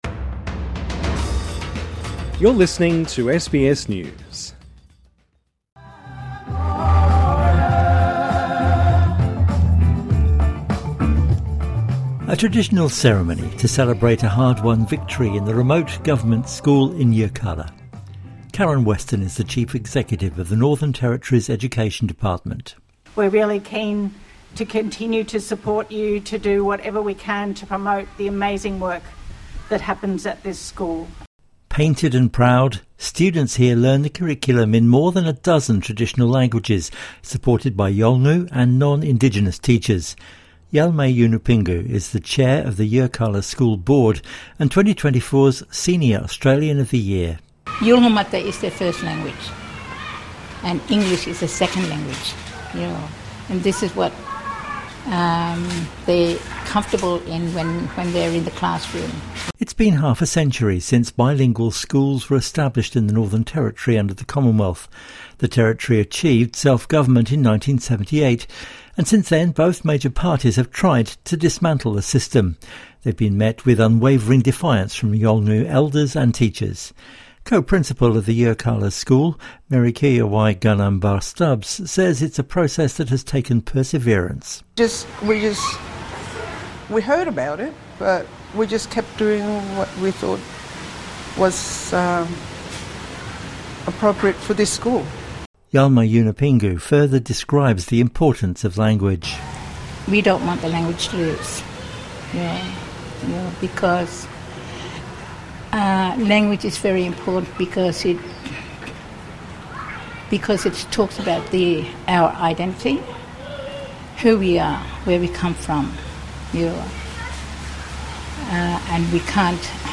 Celebration at Yirrkala school (SBS) Source: SBS News